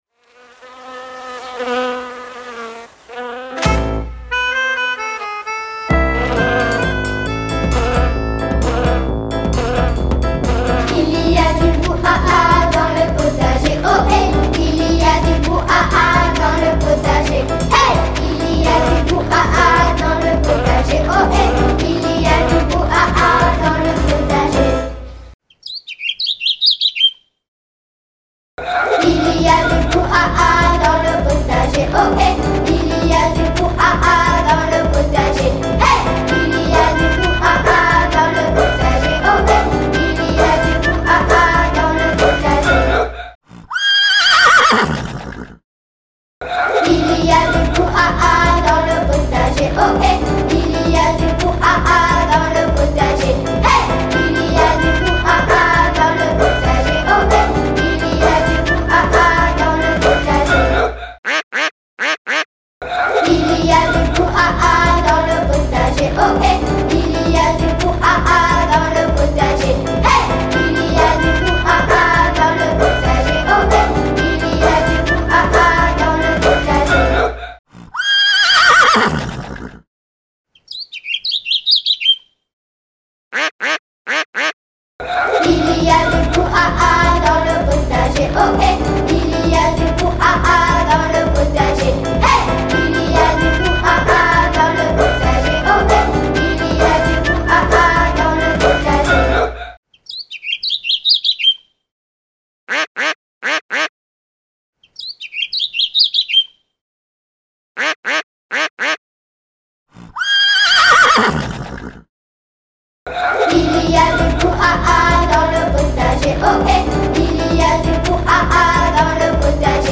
Sauras-tu reconnaître les bruits d’animaux et associer le bon geste?
Il-y-a-du-brouhaha-dans-le-potager-ecoute-active.mp3